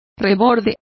Complete with pronunciation of the translation of flange.